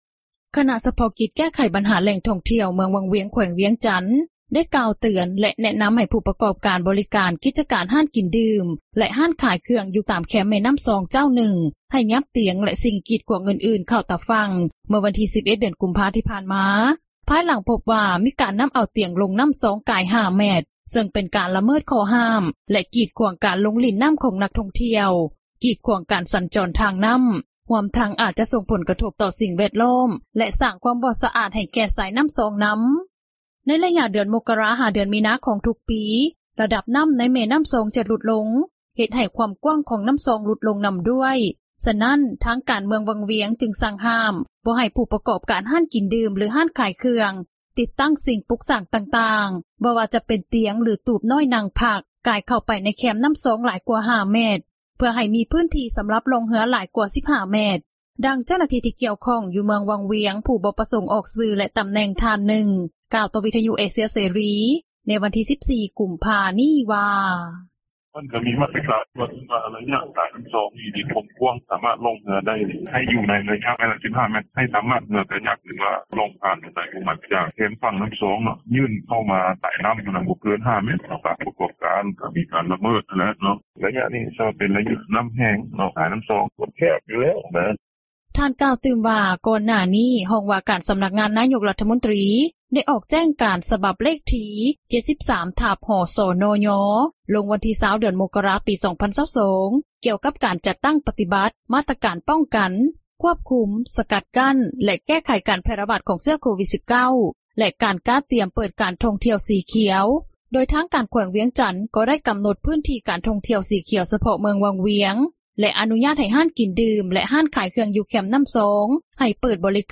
ດັ່ງເຈົ້າຂອງຮ້ານອາຫານ ຢູ່ແຄມນໍ້າຊອງ ເມືອງວັງວຽງ ນາງນຶ່ງ ກ່າວຕໍ່ວິທຍຸເອເຊັຽເສຣີ ໃນມື້ດຽວກັນນີ້ວ່າ:
ດັ່ງເຈົ້າຂອງທຸຣະກິຈເຮືອກາຍັກ ຢູ່ເມືອງວັງວຽງ ອີກນາງນຶ່ງ ກ່າວວ່າ: